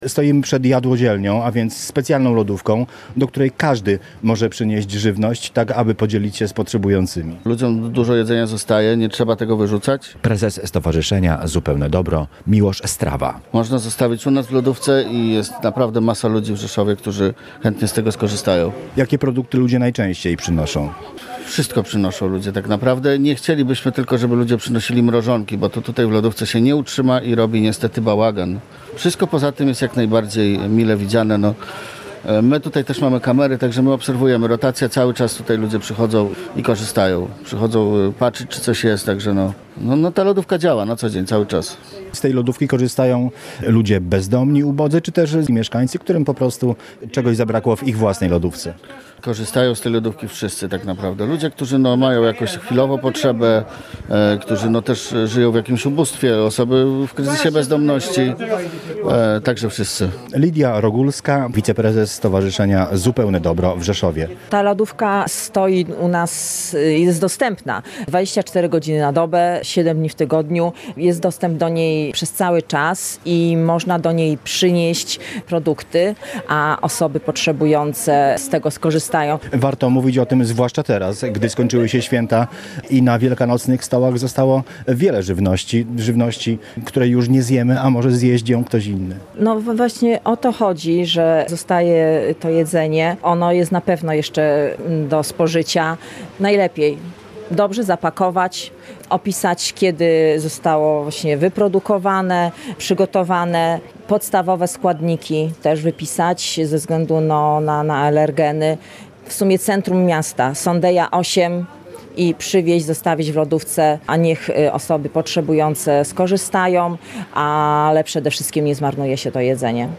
Relacje reporterskie • Rzeszowskie stowarzyszenie Zupełne Dobro zachęca do dzielenia się żywnością, która pozostała po spotkaniach przy świątecznym stole.